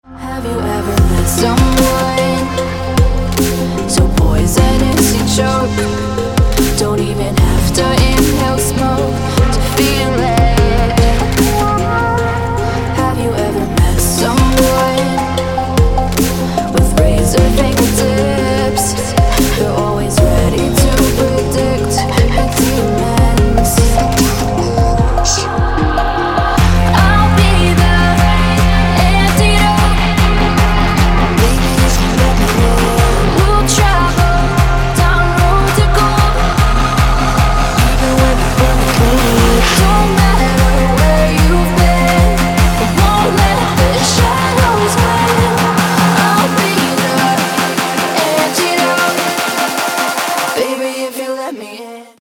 • Качество: 160, Stereo
женский вокал
dance
Electronic
EDM
vocal